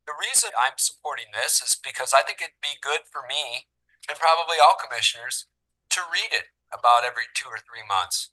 Vice chair John Taylor says it demonstrates their commitment to addressing the issue.